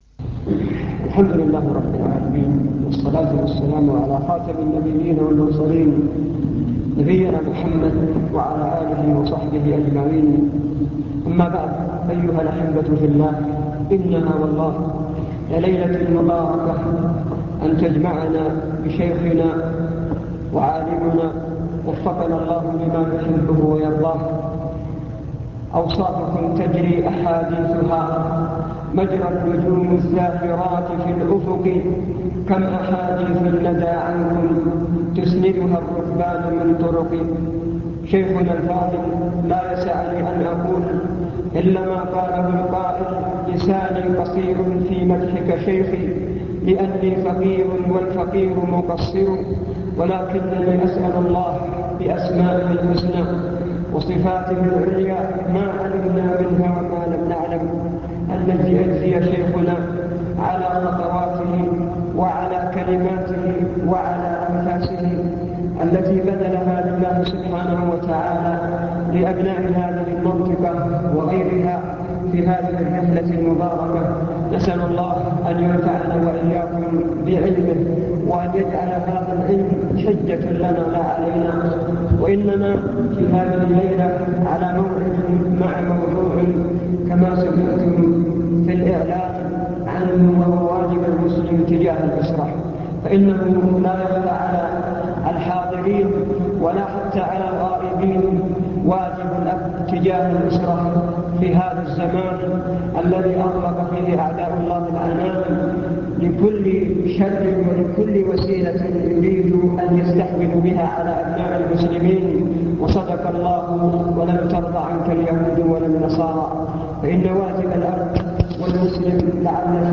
المكتبة الصوتية  تسجيلات - محاضرات ودروس  محاضرة واجب المسلم نحو أسرته
تقديم